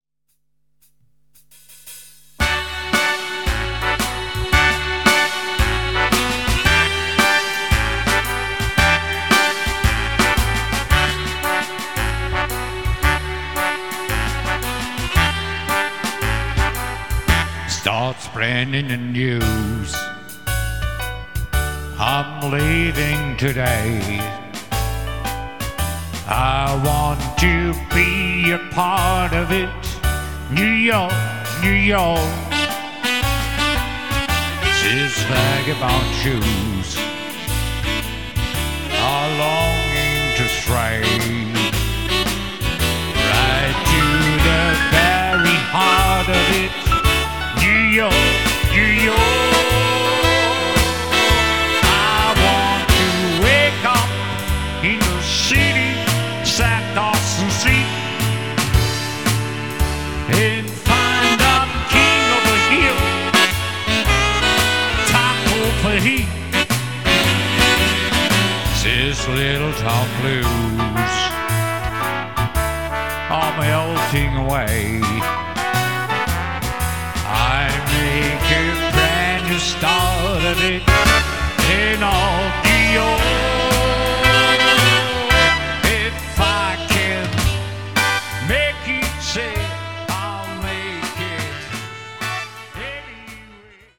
Alleinunterhalter mit Keyboard & Gesang
KEYBOARD & GESANG